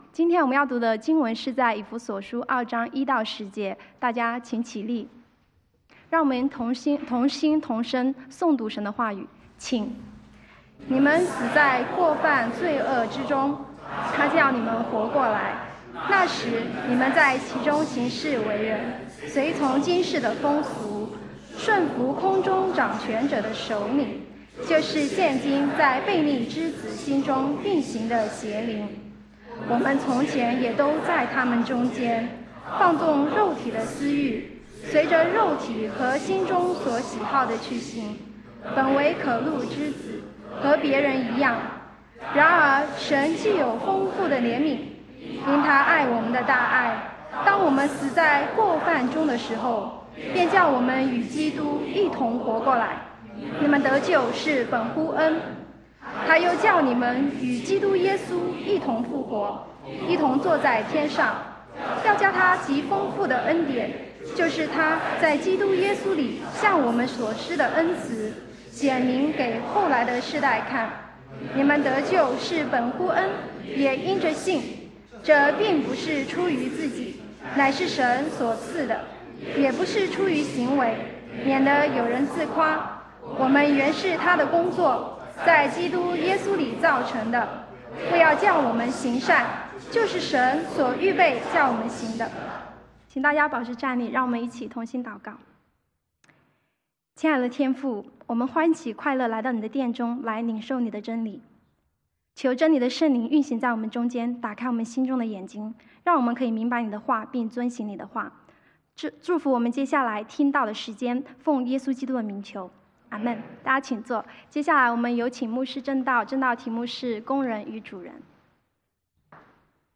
工人證道系列 | Series | Chinese Baptist Church of West Los Angeles